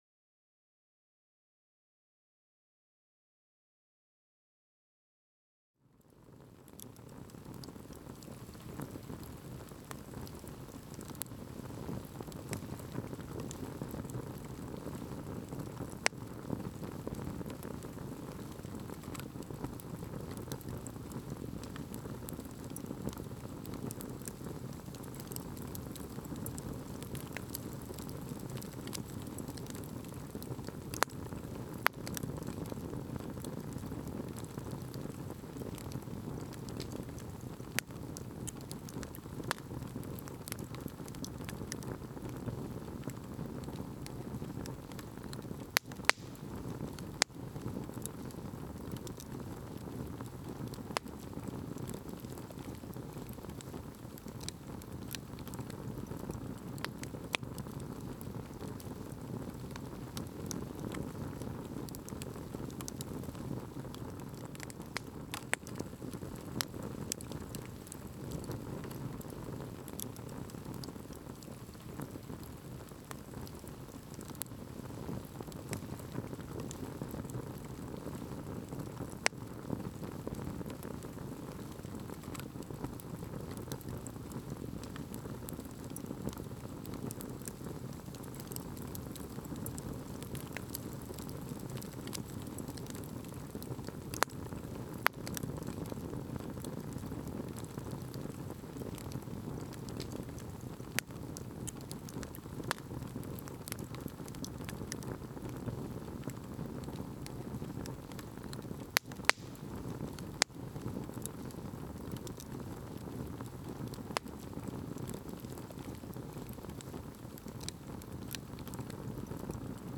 SFX_Scene04_FireSmall.ogg